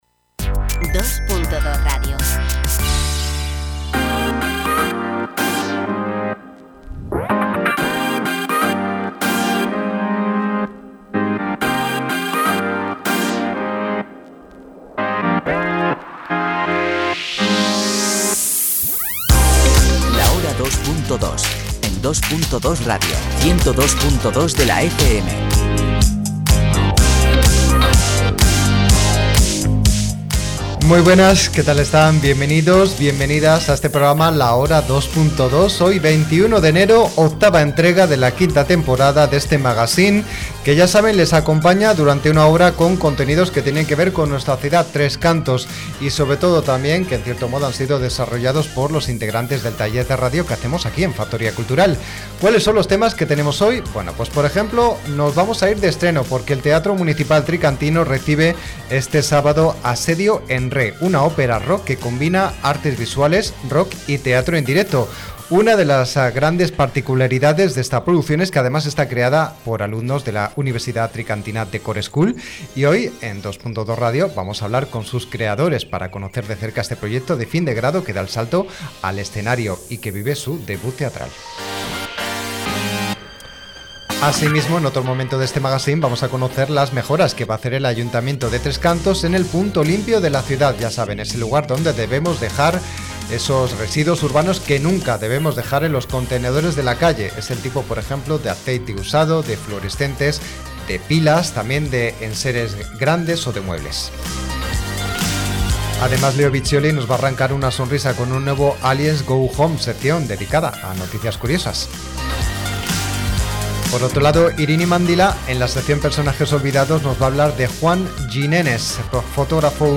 Hoy 21 de enero de 2026 es la octava entrega de la quinta temporada del magazine La Hora 2.2 de Dos.Dos Radio Formación. Les acompañaremos durante una hora con contenidos que han desarrollado los integrantes del taller de radio que hacemos en Factoría Cultural.